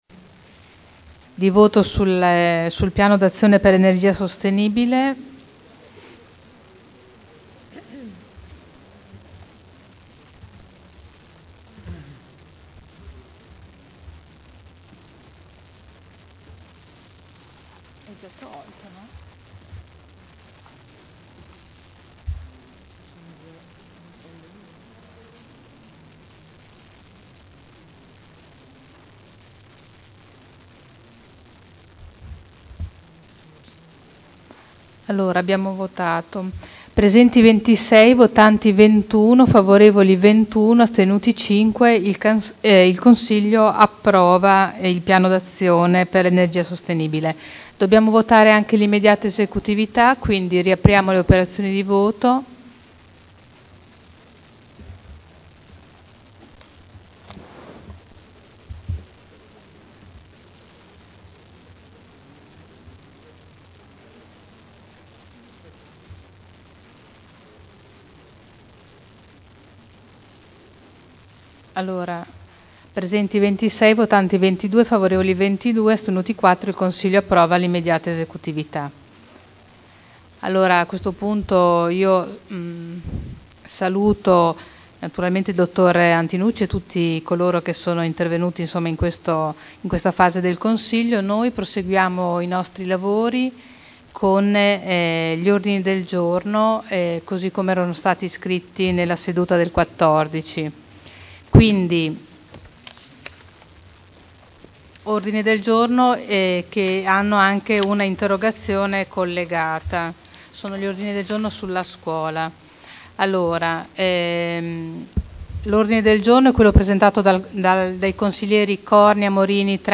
Seduta del 18/07/2011. Piano d’Azione per l’Energia Sostenibile (SEAP) Votazione su delibera e immediata esecutività